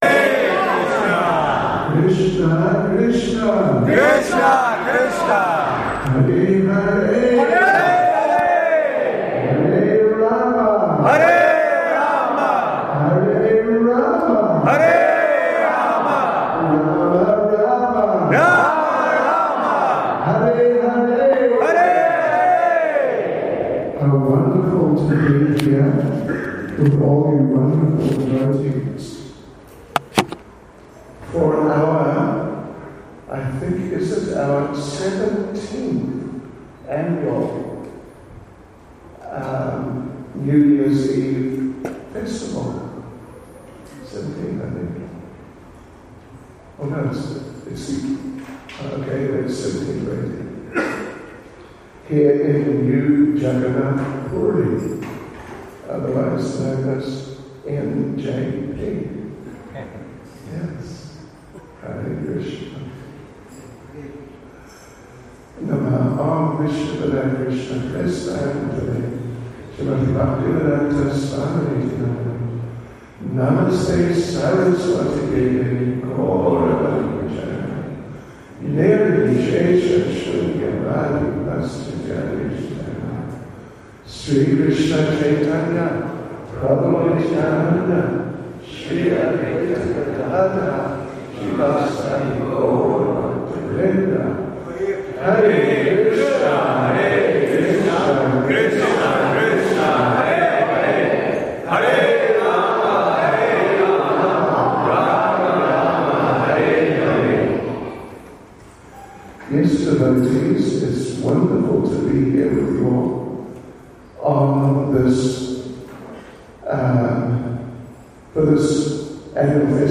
New Years Eve
ISKCON Phoenix, South Africa